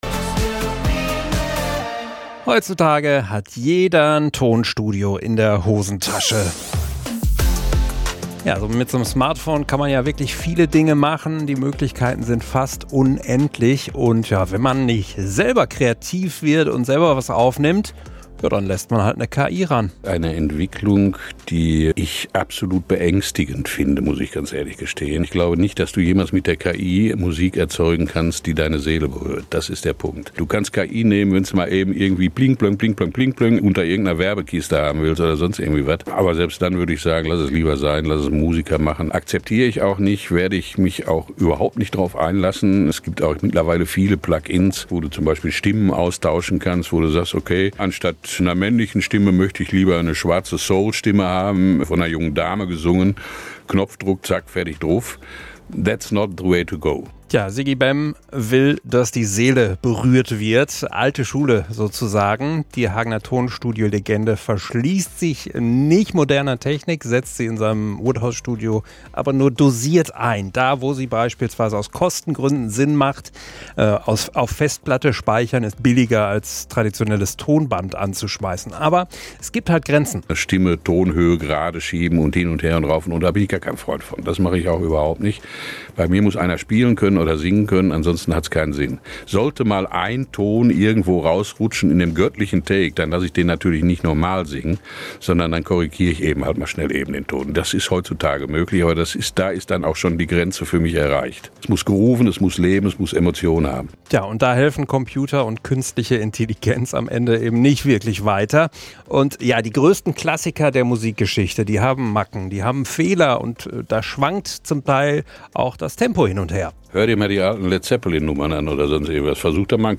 MITSCHNITT AUS DER SENDUNG II